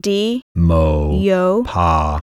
Both grammars involved sequences of syllables spoken by a female and male speaker - A syllables were spoken by the female and were ba, di, yo, tu, la, mi, no, or wu; B syllables were spoken by the male and were pa, li, mo, nu, ka, bi, do, or gu.
di-mo-yo-pa.wav